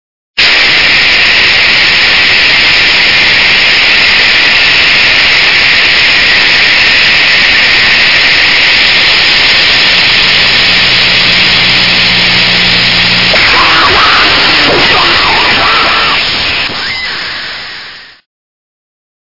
絶叫とノイズが宇宙空間と融合を果たした奇跡のコズミックミュージック！